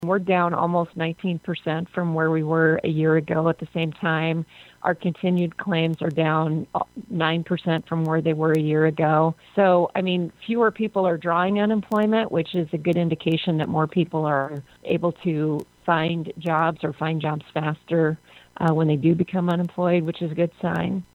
TOWNSEND SAYS THE UNEMPLOYMENT INSURANCE CLAIM NUMBERS ARE ANOTHER INDICATOR OF WHAT’S HAPPENING IN THE JOB MARKET.